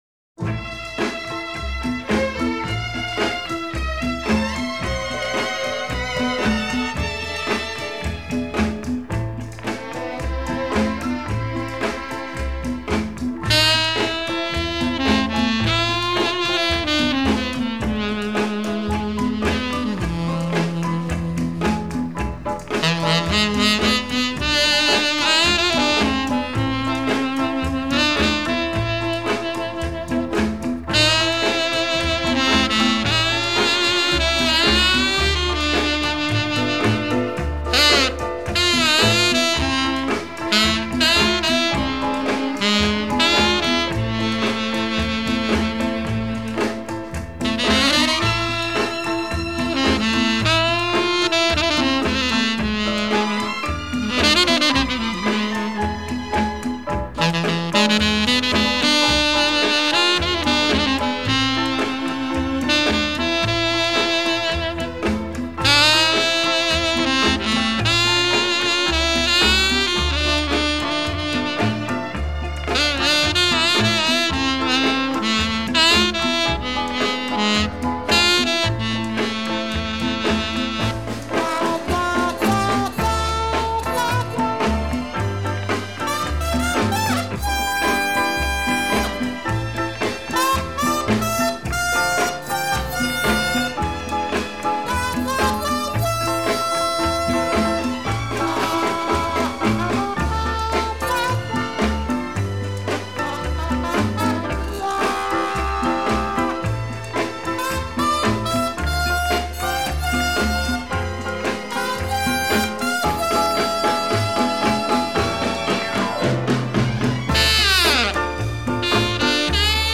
Прекрасная мелодия и блестящий саксофон